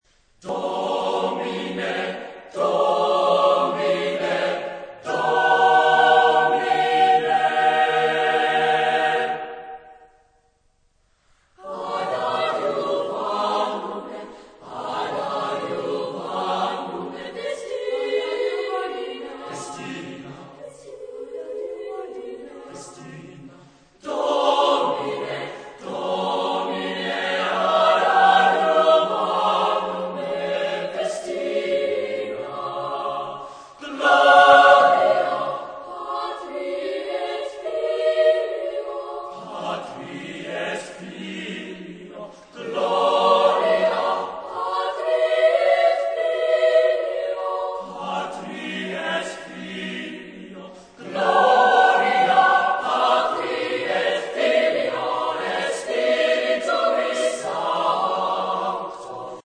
Epoque : 17 ; 18ème s.
Genre-Style-Forme : Sacré
Type de choeur : SSATBB  (6 voix mixtes )
interprété par Drakensberg Boys' Choir